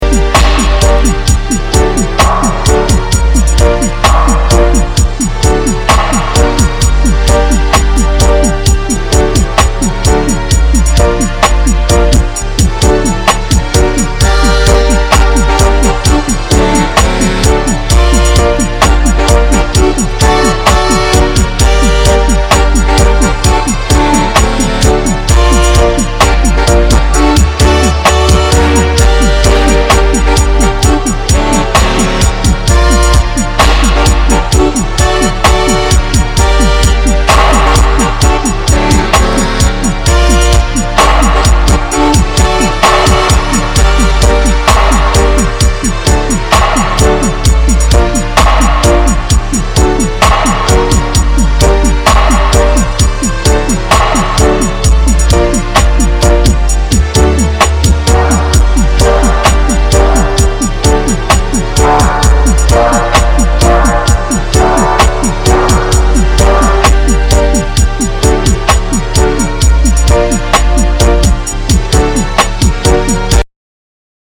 ストリクトリールーツな世界観を表現した
REGGAE & DUB / ROOTS & CULTURE